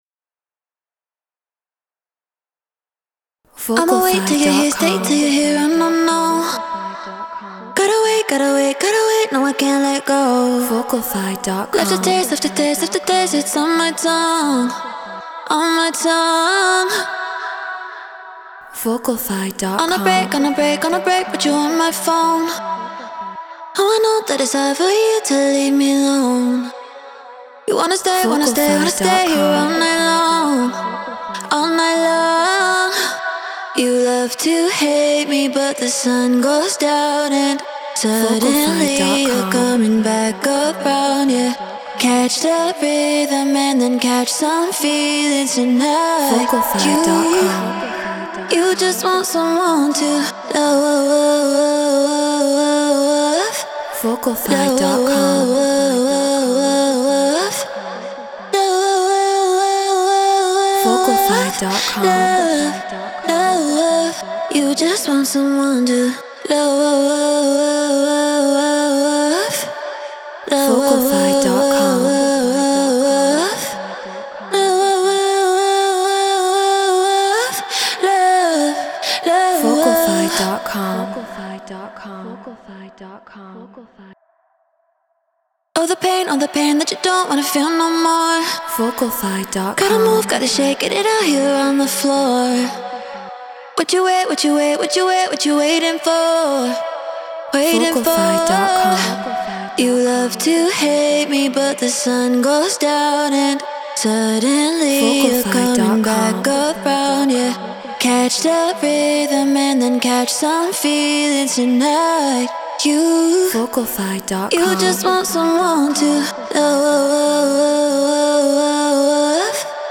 Afro House 120 BPM Bmin
Shure SM7B Scarlett 2i2 4th Gen Ableton Live Treated Room